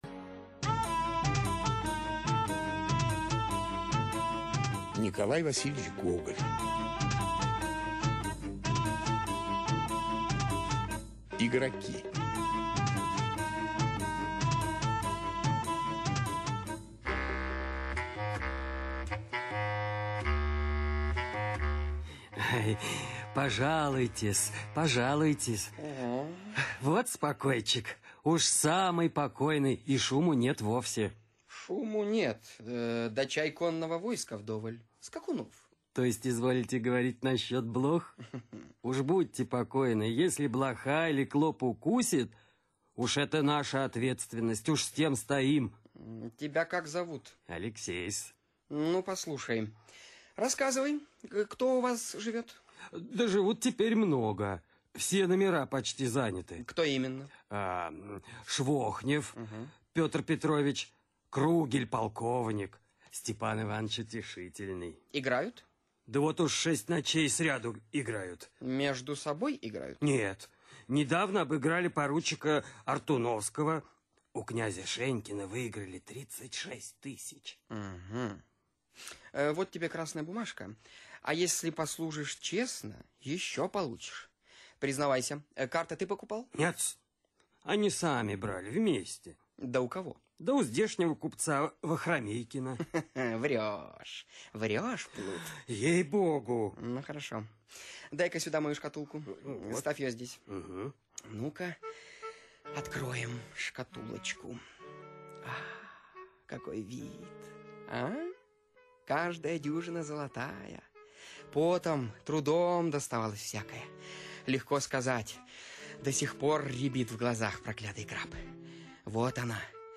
Аудиокнига Игроки
Качество озвучивания весьма высокое.